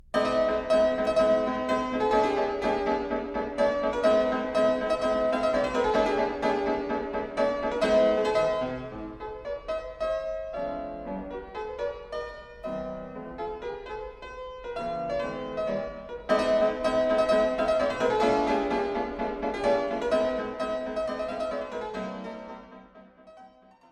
Uitgevoerd op fortepiano door Alexei Lubimov.